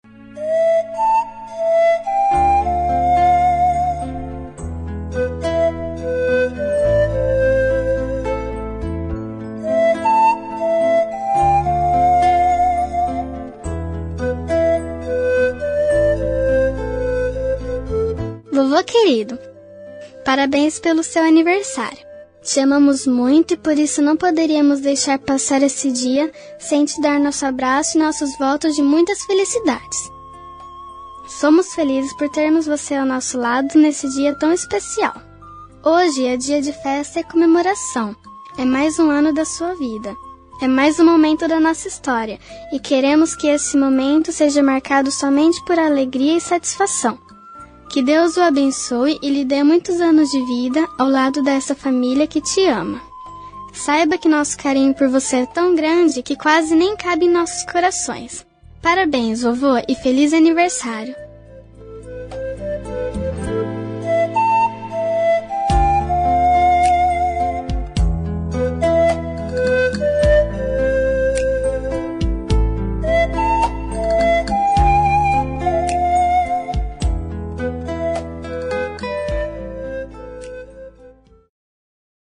Aniversário Voz Infantil Avô – Voz Feminina – Cód: 256524